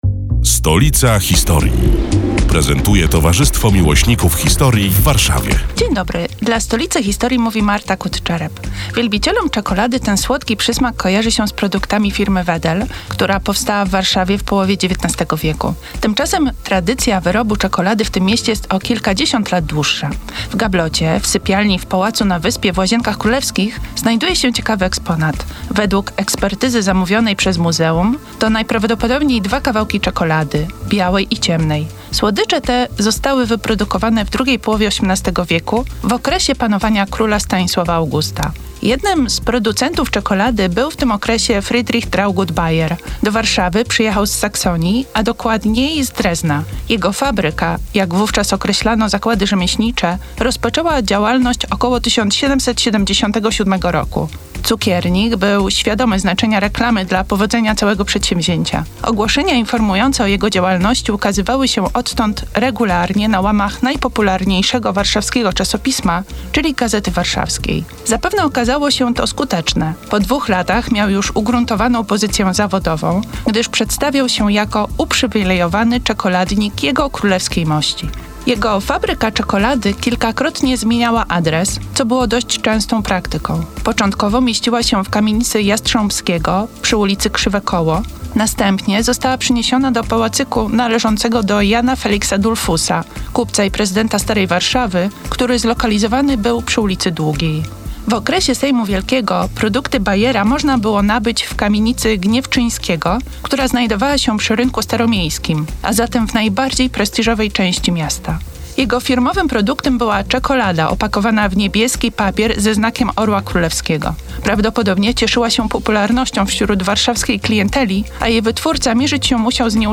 Felieton pod wspólną nazwą: Stolica historii. Przedstawiają członkowie Towarzystwa Miłośników Historii w Warszawie, które są już od ponad półtora roku emitowane w każdą sobotę, w nieco skróconej wersji, w Radiu Kolor.